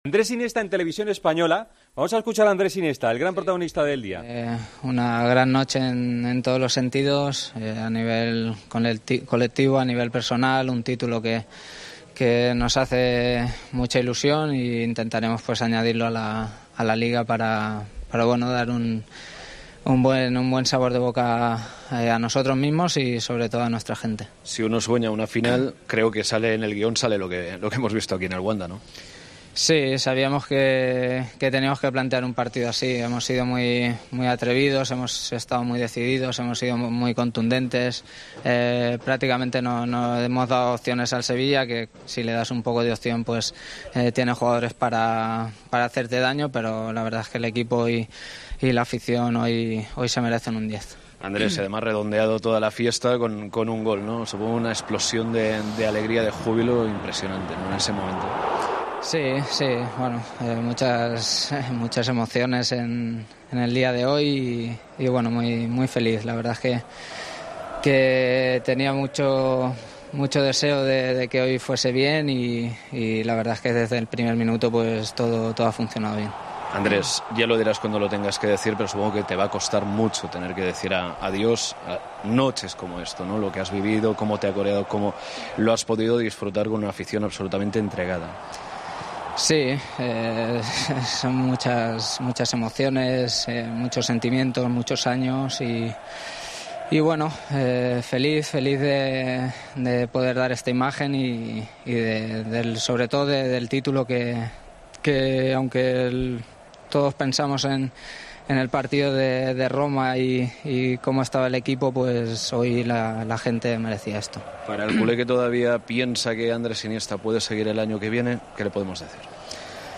Admitió en la zona mixta del Wanda Metropolitano que de esta jornada se queda "con todo" después de "un partido redondo" y valoró "la sensación" que había transmitido el Barcelona "a nivel colectivo", así como también terminó muy satisfecho de su actuación personal.